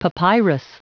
Prononciation du mot papyrus en anglais (fichier audio)
Prononciation du mot : papyrus